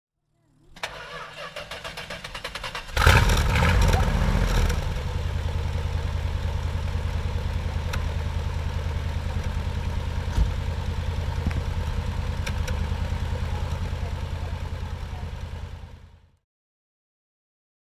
Monteverdi High Speed 375 S (1968) - Starten und Leerlauf